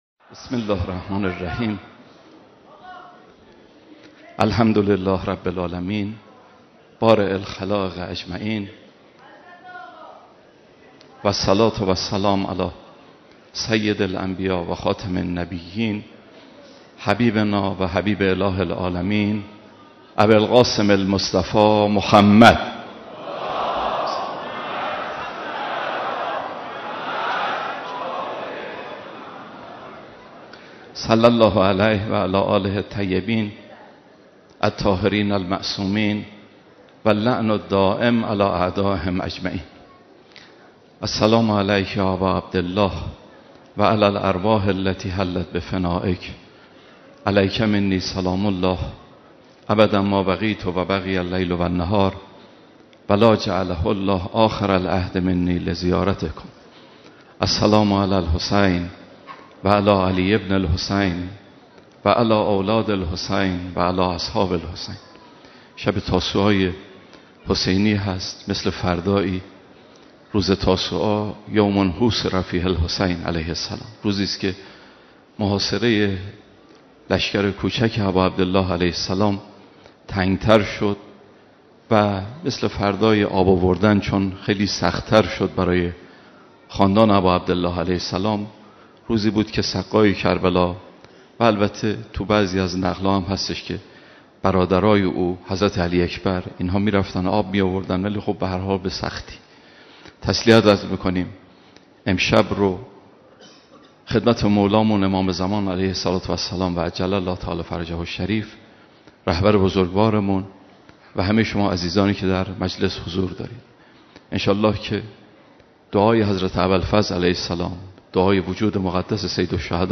یک استاد حوزه علمیه گفت: وظیفه ما استقامت در مسیر حق است و اگر در مقابل دشمن احساس ضعف نکنیم، به آرامش خواهیم رسید.